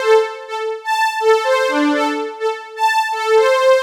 cch_synth_loop_chitown_125_Dm.wav